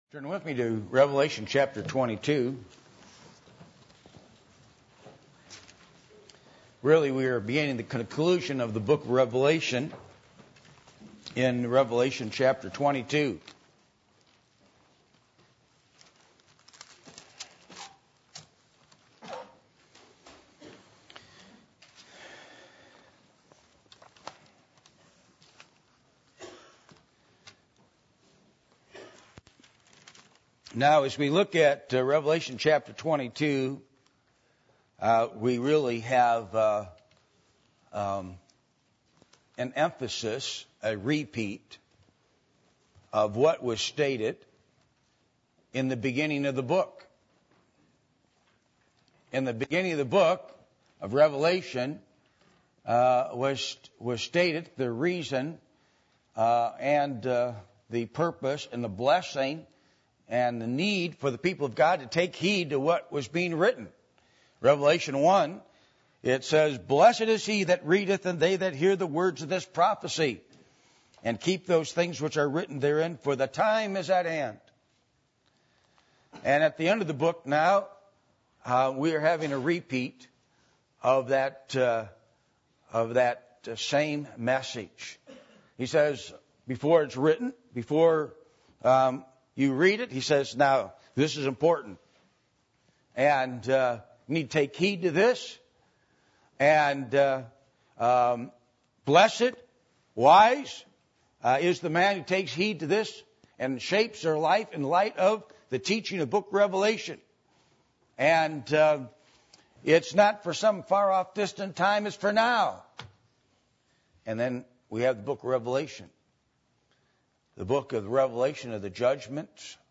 Passage: Revelation 22:6-21 Service Type: Sunday Morning %todo_render% « What Happened In The Incarnation?